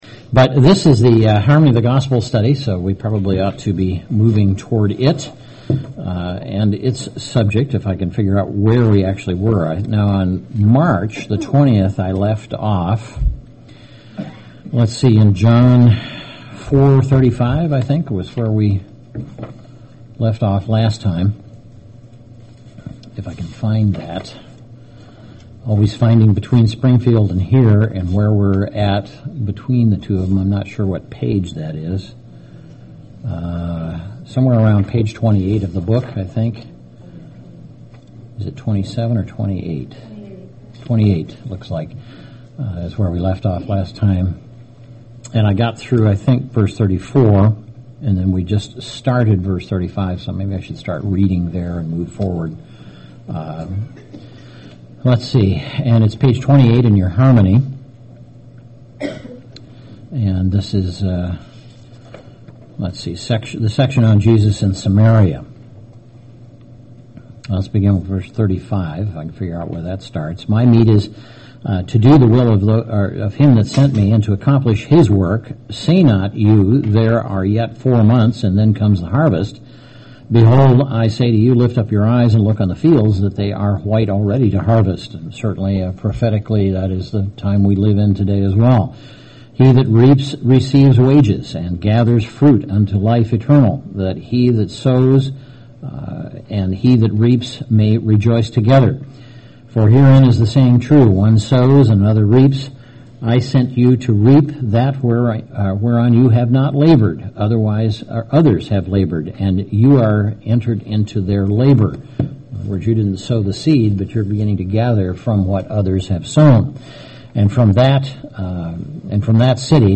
A Bible study on Sections 35-43 of the Harmony of the Gospels, beginning in John 4:35 with Jesus in Samaria.
UCG Sermon Studying the bible?